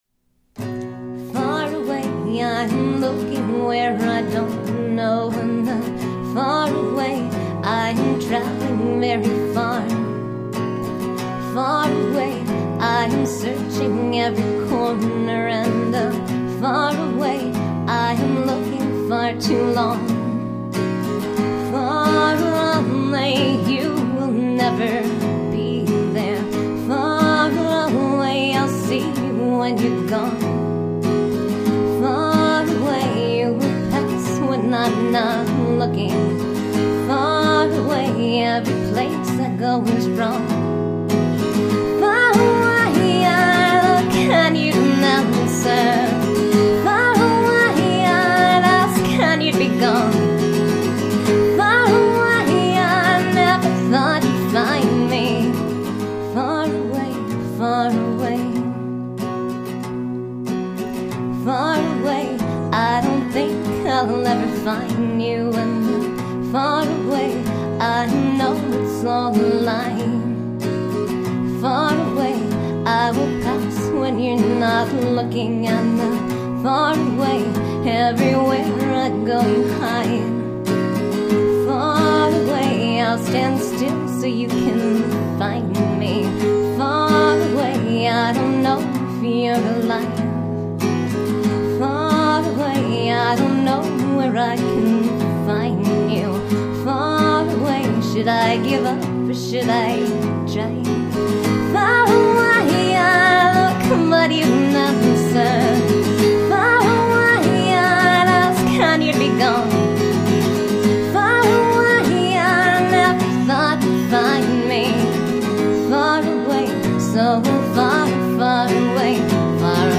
64kbps mono MP3
guitar and vocals